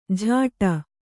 ♪ jhāṭa